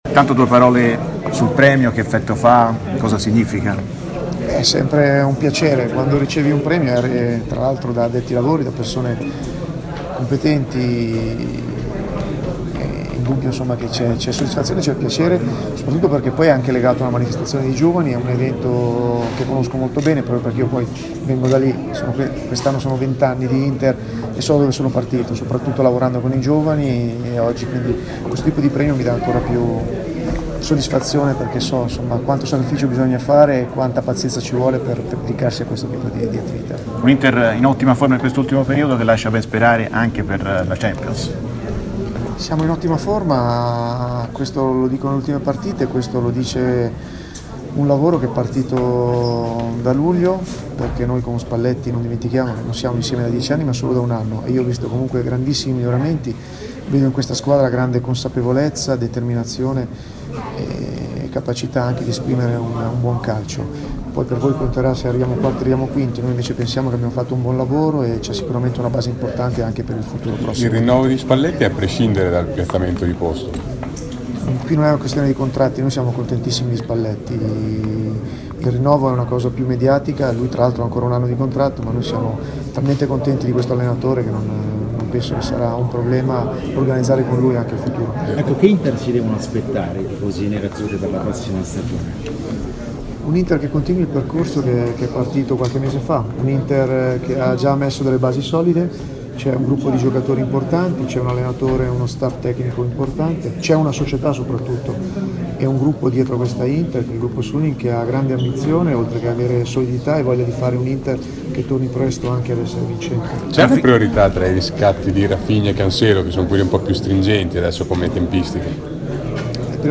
Dopo aver ricevuto il premio Maestrelli, il direttore sportivo dell'Inter, Piero Ausilio, è intervenuto ai microfoni di RMC Sport: